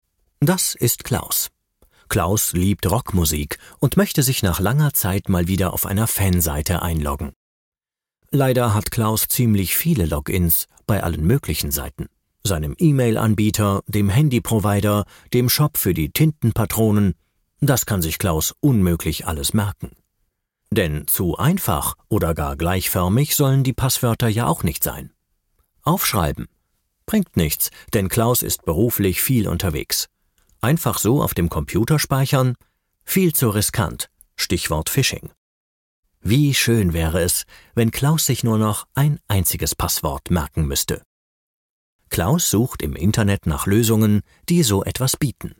Male
Assured, Authoritative, Confident, Corporate, Engaging, Friendly, Natural, Reassuring, Warm
Documentary.mp3
Microphone: Brauner VM 1, AKG414 B